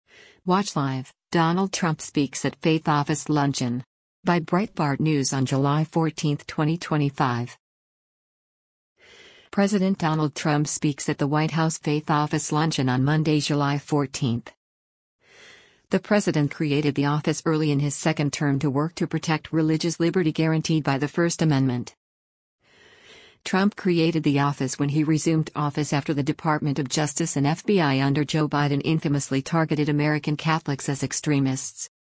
President Donald Trump speaks at the White House Faith Office luncheon on Monday, July 14.